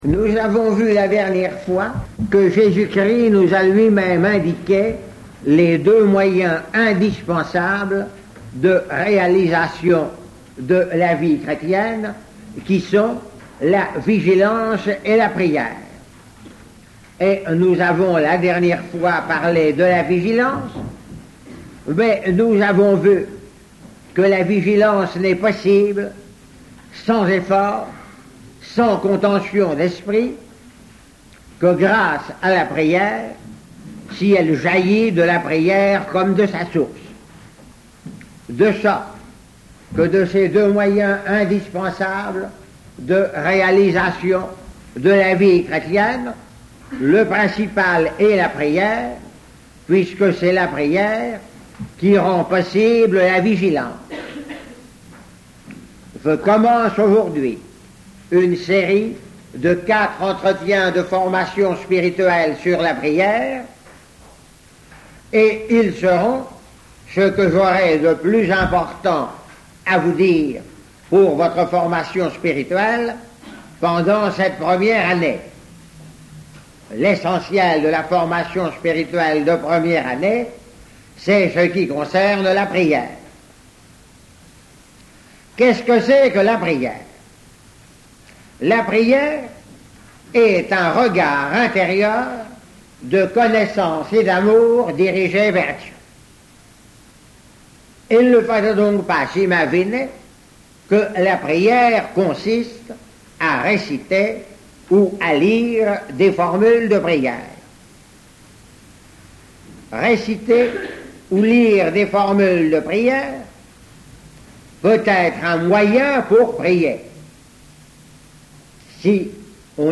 Enseignement
Il s'agit de documents d'archive dont la qualité technique est très médiocre, mais dont le contenu est particulièrement intéressant et tout à fait conforme à l'enseignement de l'Eglise Catholique.